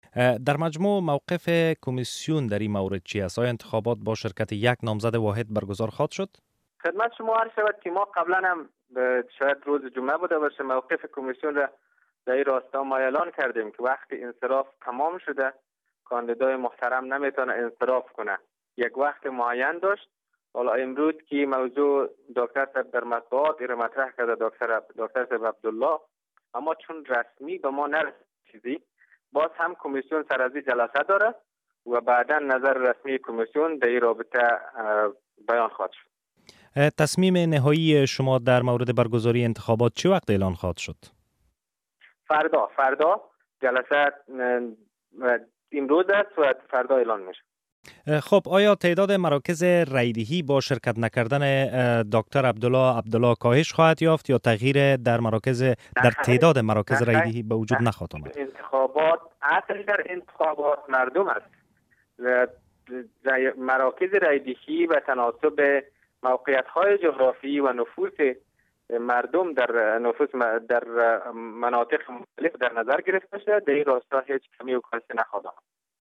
مصلحبه با داود علی نجفی رییس دارالانشاء کمسیون مستقل انتخابات افغانستان